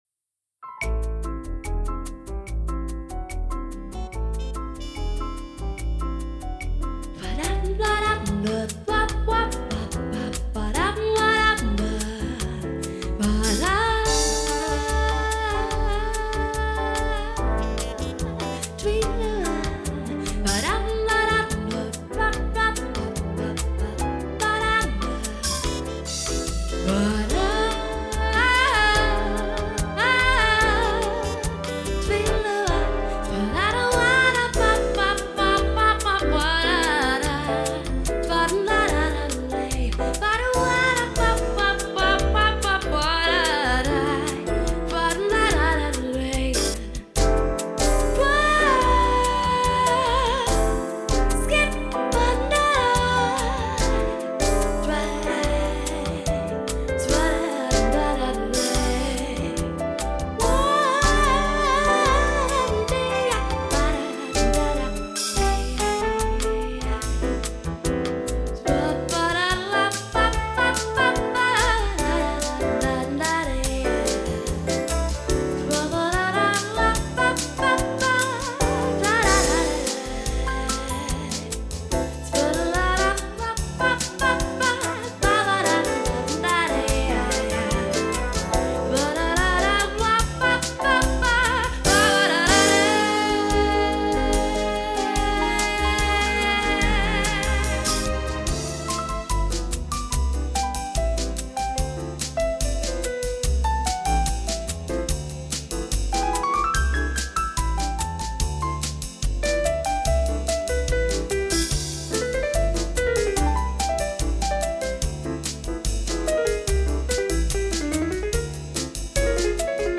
- Jazz/World music
Клавишные